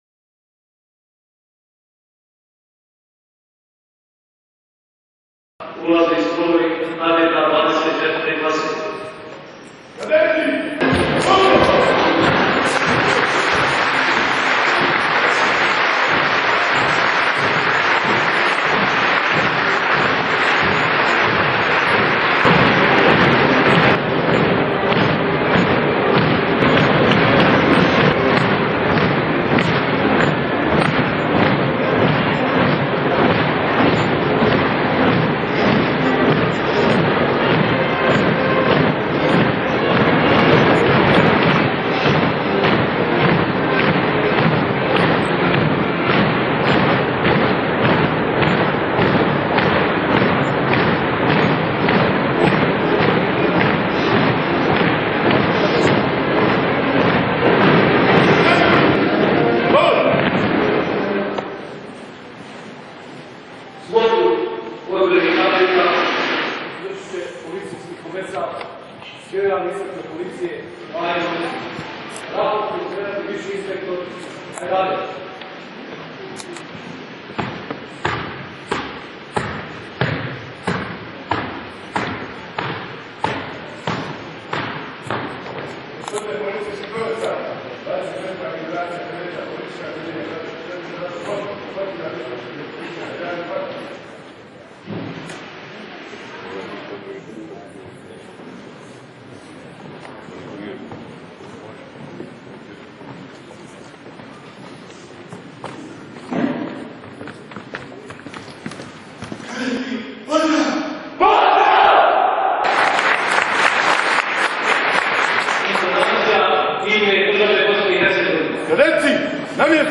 Svečano polaganje zakletve